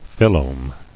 [phyl·lome]